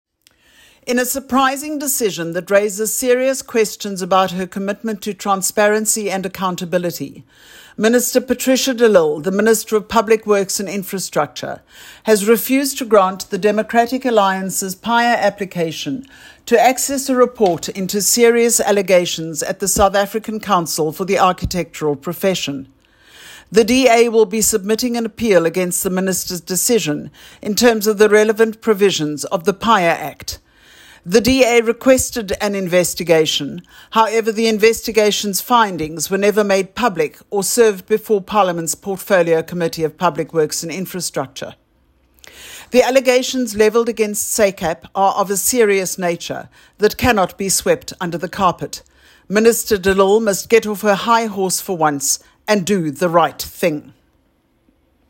soundbite by Madeleine Hicklin MP.